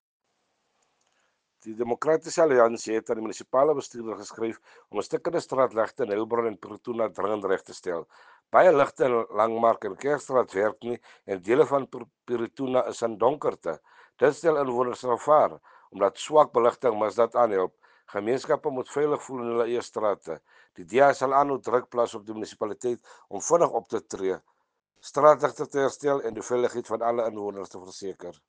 Afrikaans soundbites by Cllr Robert Ferendale and